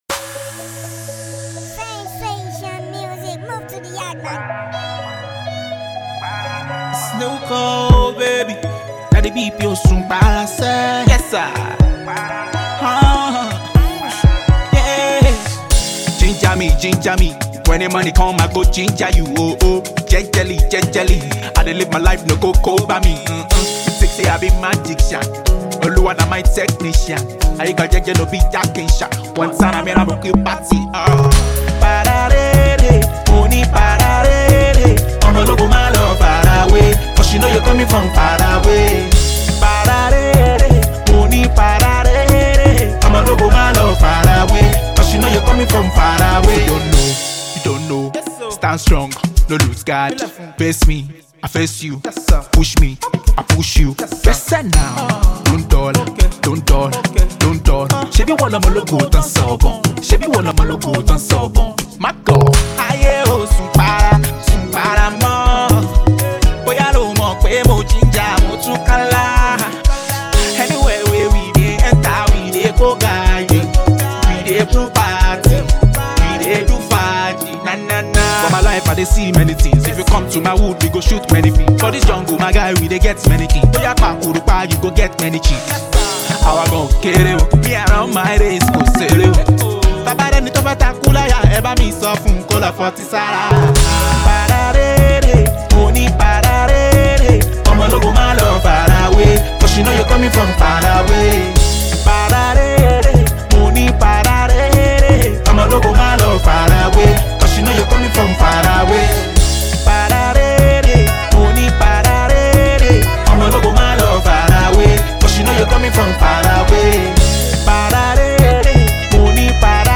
irresistible beats that will have you tapping your feet